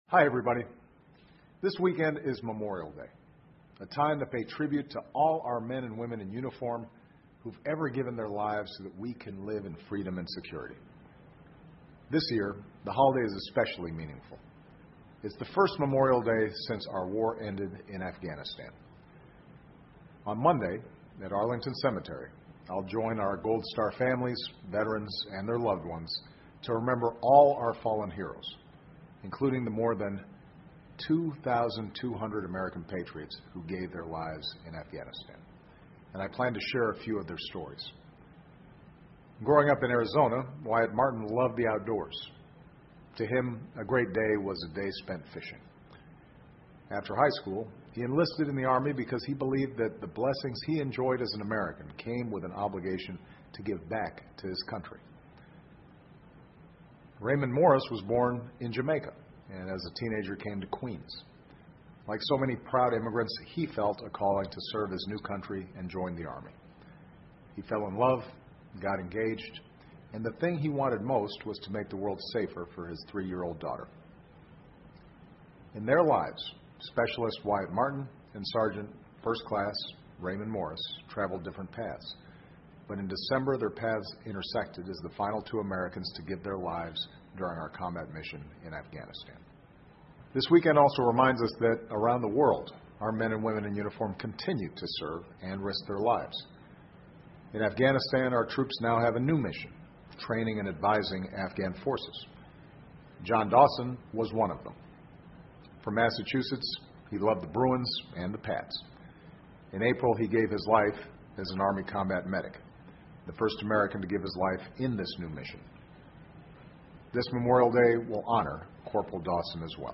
奥巴马每周电视讲话：总统阵亡将士纪念日发表讲话 致敬逝去英雄 听力文件下载—在线英语听力室